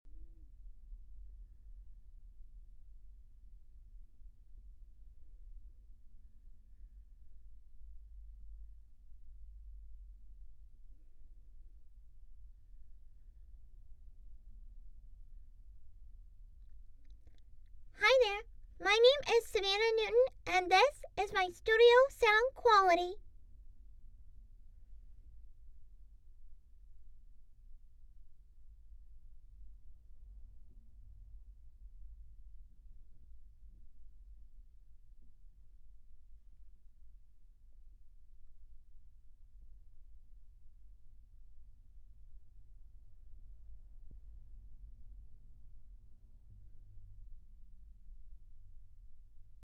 Female
TEENS, 20s
Approachable, Bright, Bubbly, Character, Cheeky, Children, Conversational, Energetic, Engaging, Friendly, Funny, Natural, Sarcastic, Soft, Versatile, Wacky, Warm, Witty, Young
Animation, Audiobook, Character, Commercial, Narration, Video Game
American Southern American (native)
Microphone: Neuman U87
Audio equipment: Focusrite Interface , Acoustically treated booth, Source-Connect, Mac Book Pro with remote iPad monitor in booth